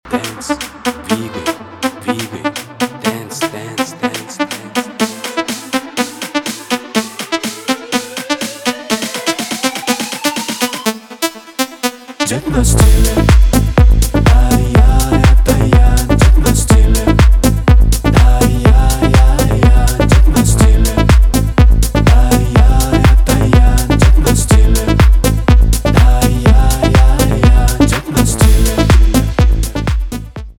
электроника
танцевальные , нарастающие , битовые , качающие